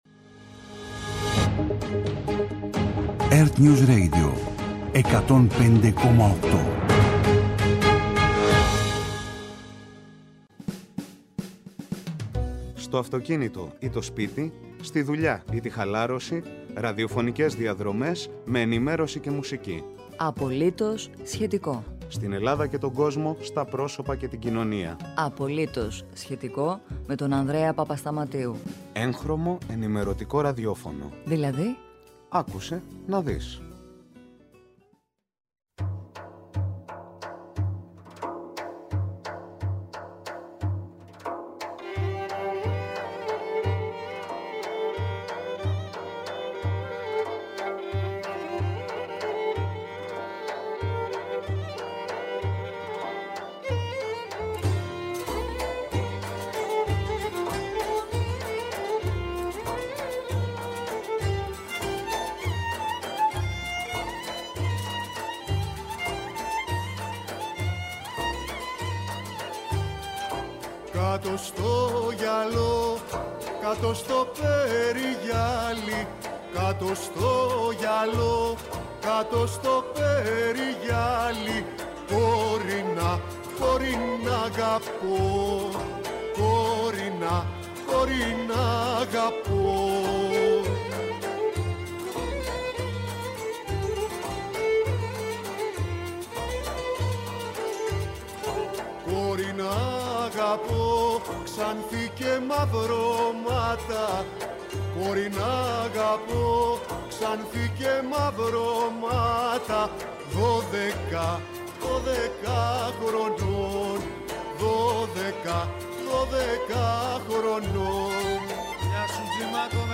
-Ο Δήμαρχος Τυρνάβου, Αστέρης Τσικριτσής, για Τσικνοπέμπτη και τις «Μπαρμπαριές», έθιμα της περιοχης μέχρι την Καθαρά Δευτέρα
αθλητική ενημέρωση